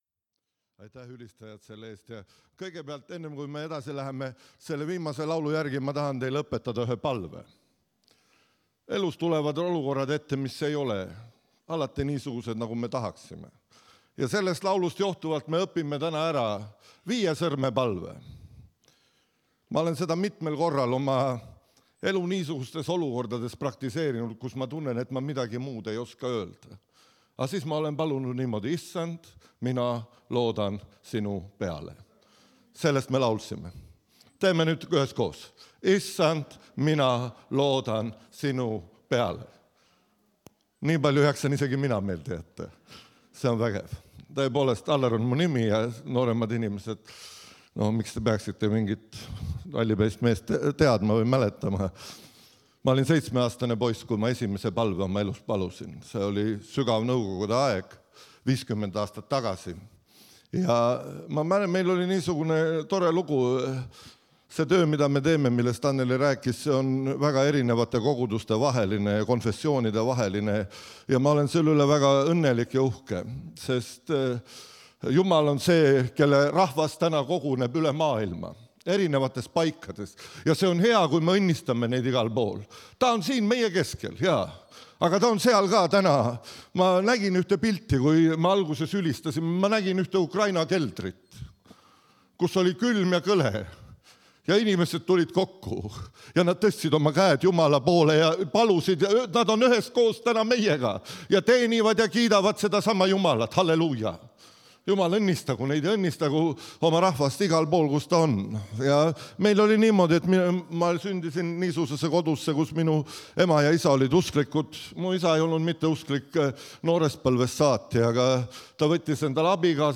Jutlused - EKNK Toompea kogudus